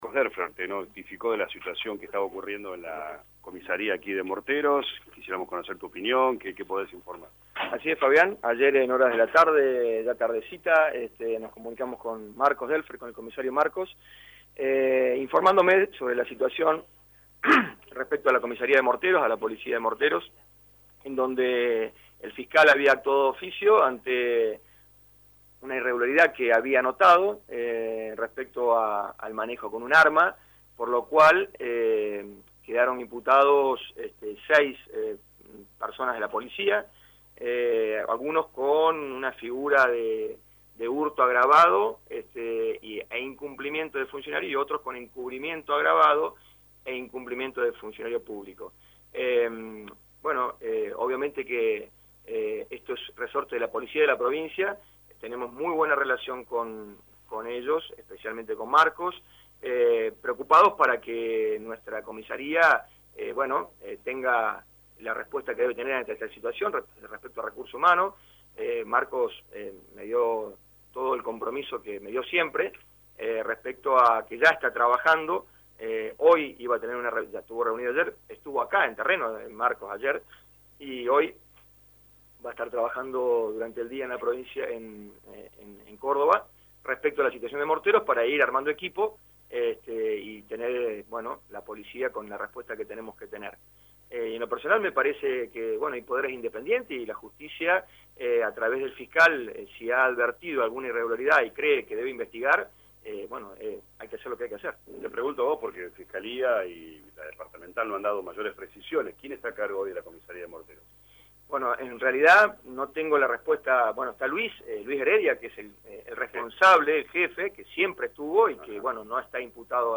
El intendente de la vecina ciudad, Dr. José Bría habló con LA RADIO 102.9 FM y manifestó su preocupación luego de conocerse las irregularidades en la Comisaría de Morteros.